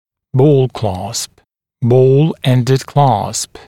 [bɔːl klɑːsp] [ˌbɔːl-‘endɪd klɑːsp] [бо:л кла:сп] [ˌбо:л-‘эндид кла:сп] пуговчатый кламмер